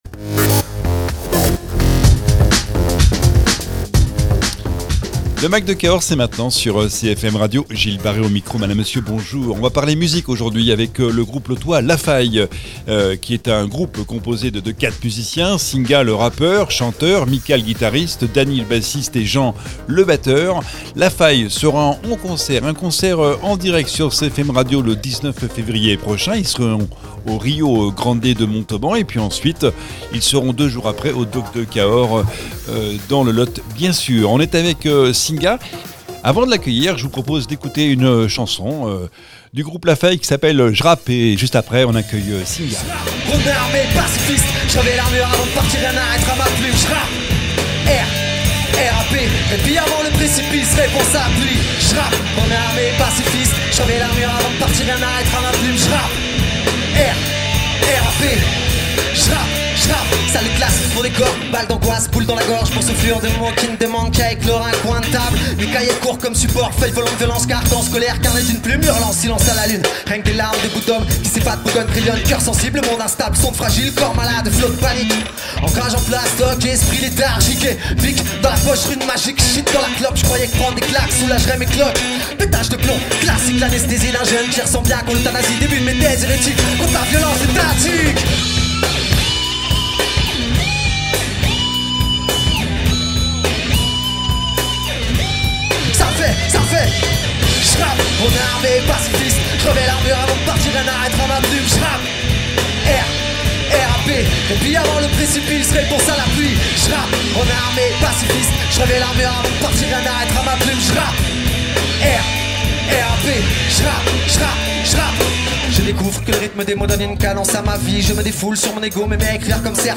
rappeur